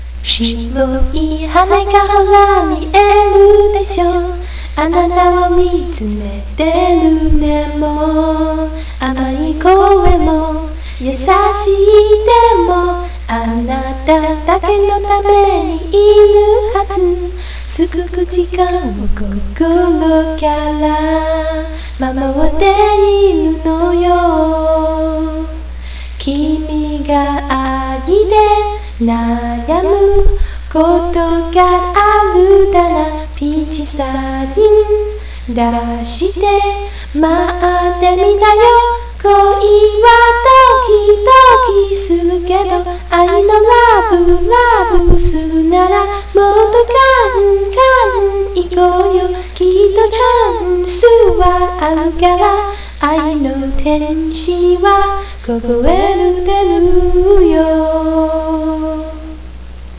- - v o c a l    .w a v - -